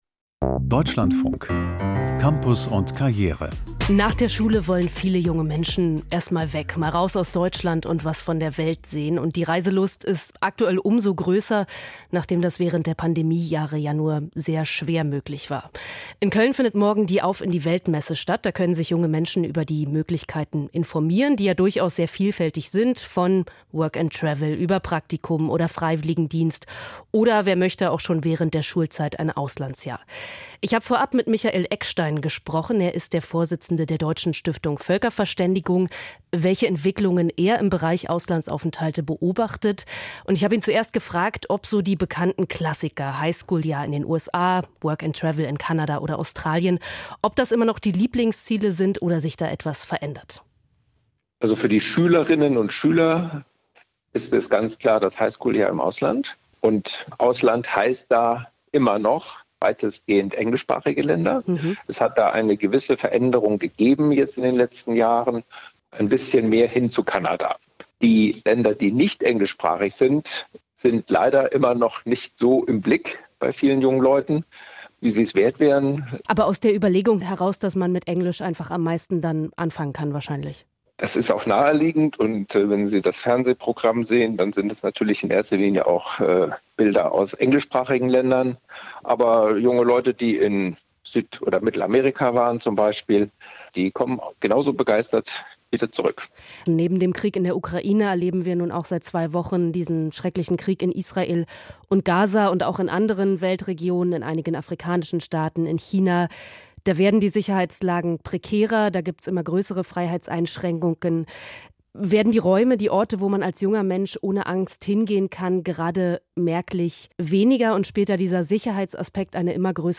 Erwartungen Jugendlicher an Auslandsaufenthalte - Interview